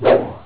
shoot.wav